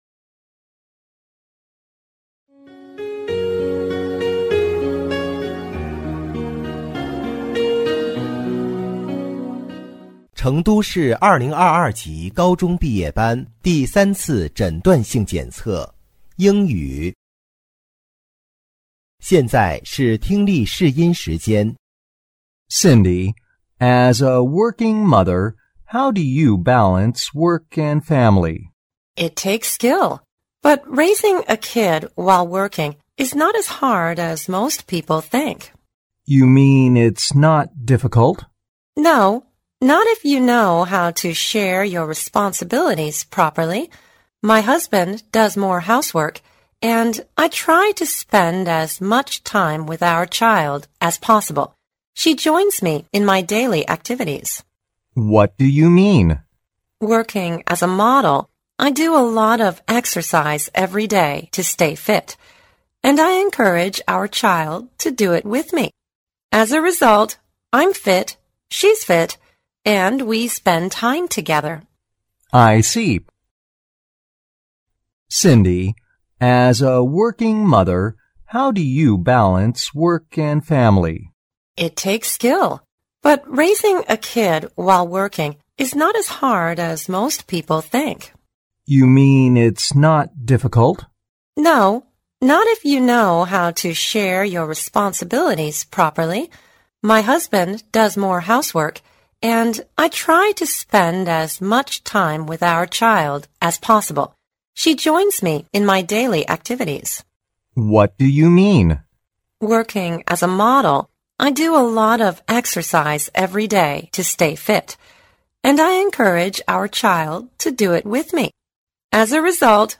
2025届成都三诊英语听力.mp3